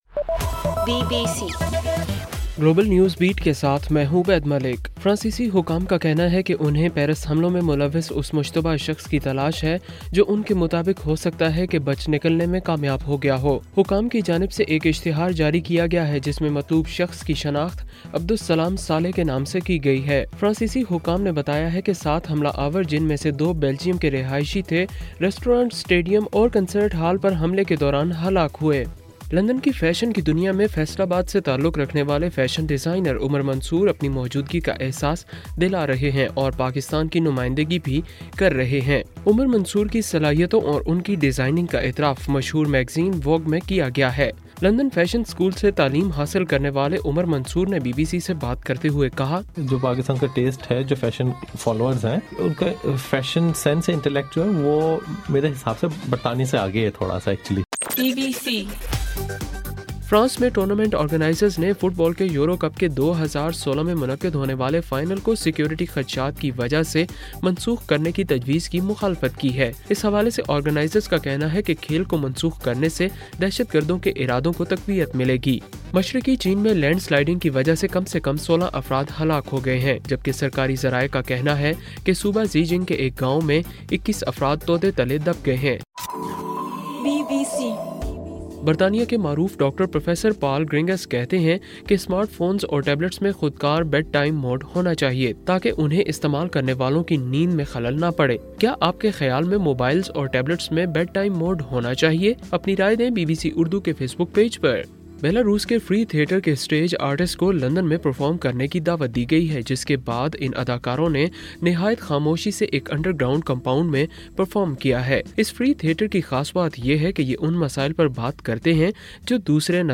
نومبر 16: صبح 1 بجے کا گلوبل نیوز بیٹ بُلیٹن